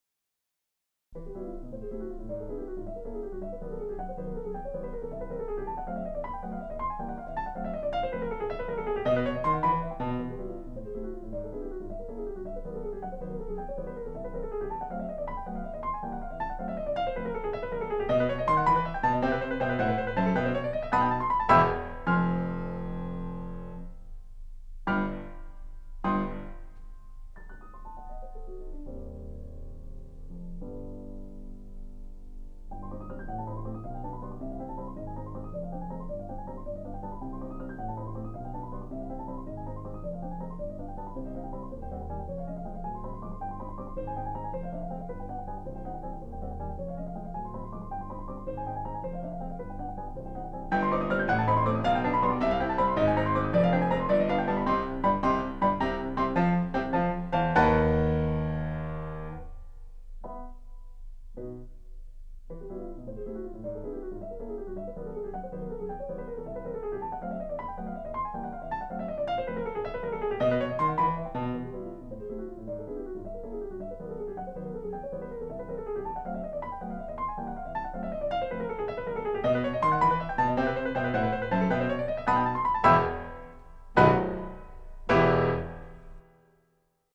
Humoreske 2 & 3 for piano